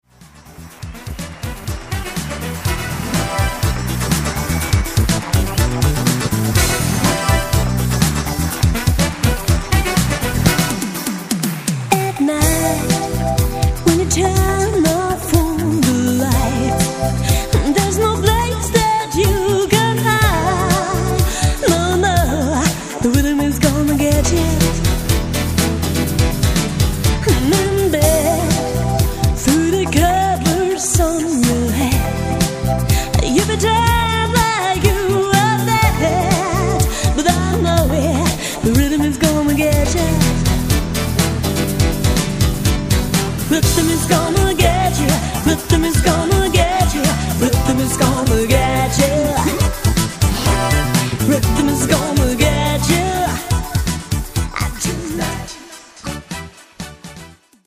Vocals, Bass, Keys, Trumpet
Guitar, Trombone
Drums